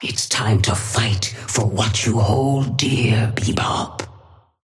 Sapphire Flame voice line - It's time to fight for what you hold dear, Bebop.
Patron_female_ally_bebop_start_04.mp3